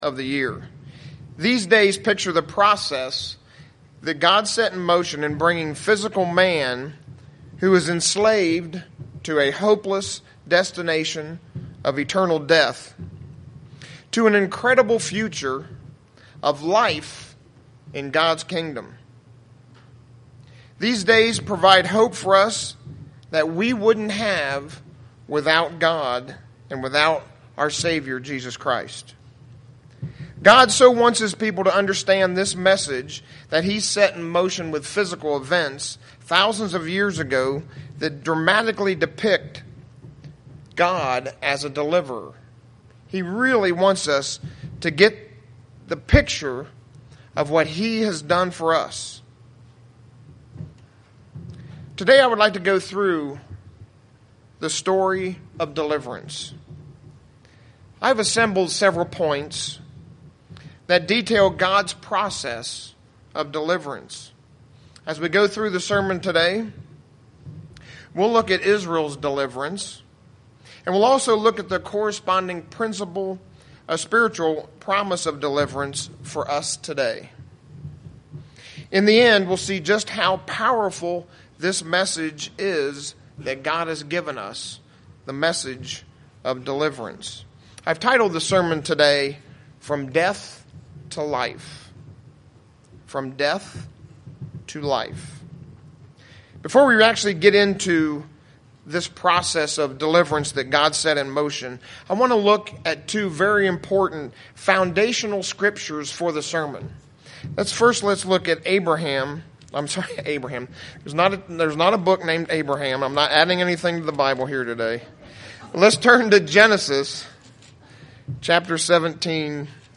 Print Look at both Ancient Israel's and our story of Deliverance UCG Sermon Studying the bible?